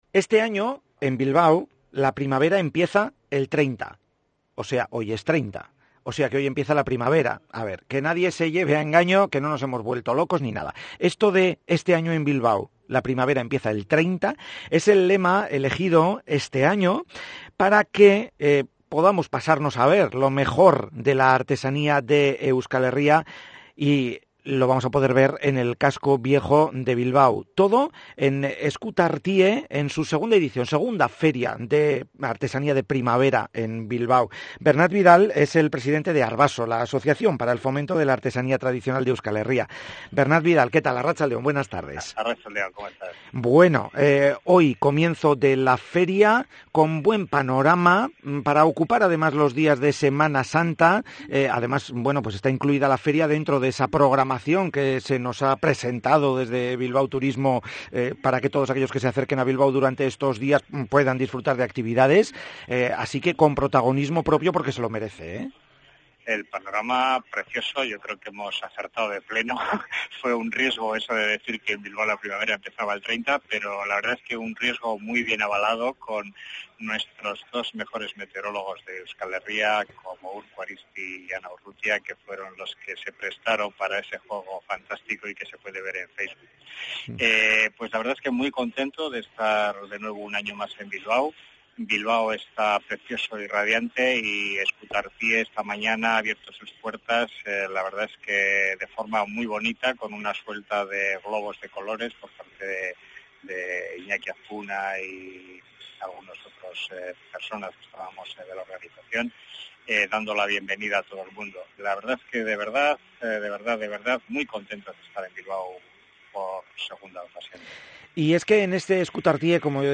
Escuchar entrevista completa (duraci�n 13:32 minutos)